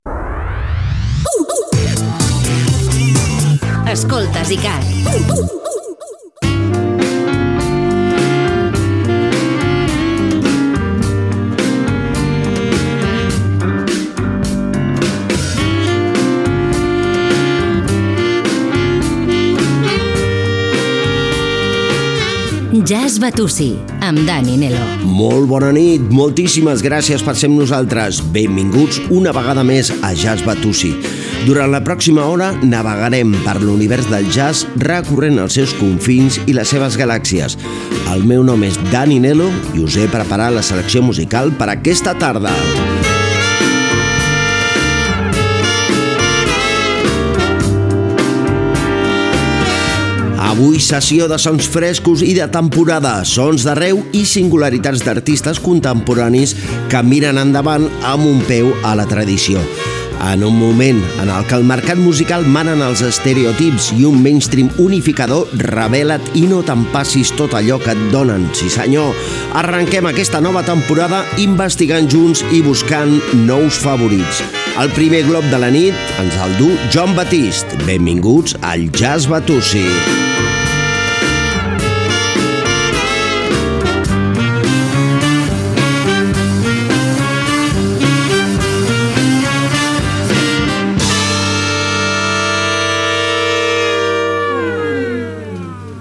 Indicatiu de la ràdio, careta del programa, benvinguda i presentació del primer programa de la temporada 2025-2026.
Musical
FM